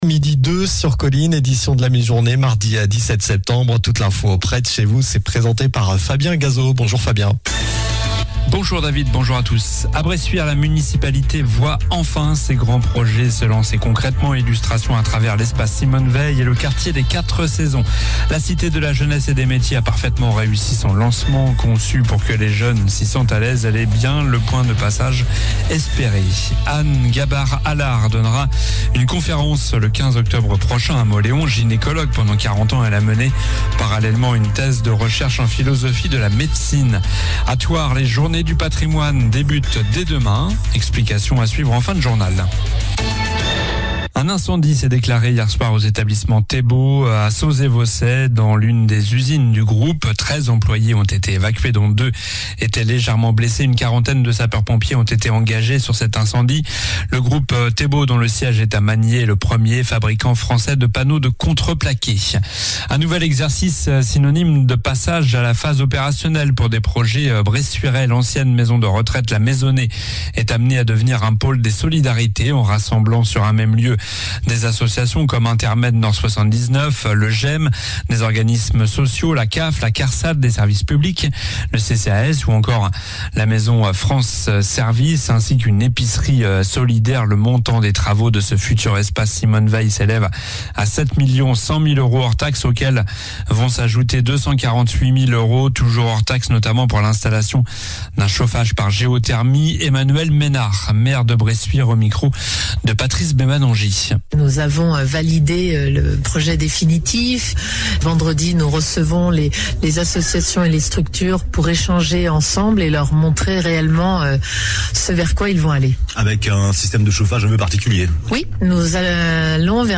Journal du mardi 17 septembre (midi)